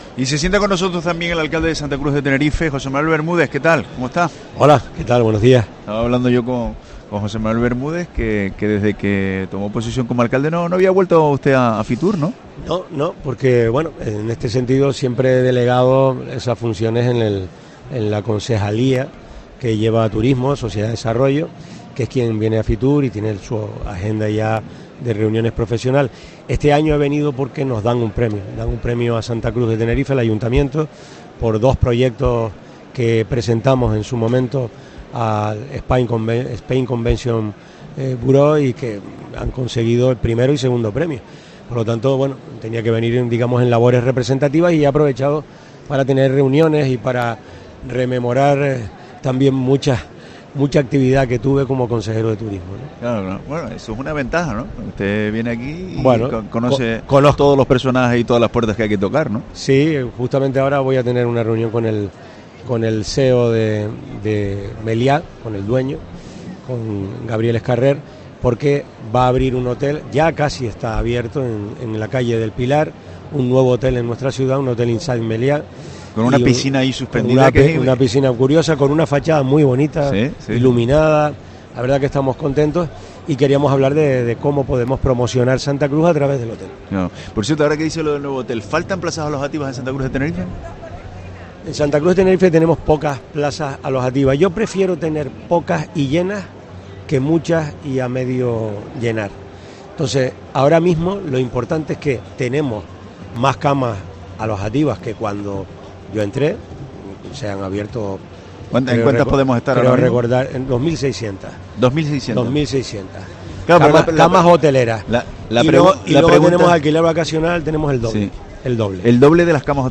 Entrevista a José Manuel Bermúdez, alcalde de Santa Cruz de Tenerife, en FITUR 2024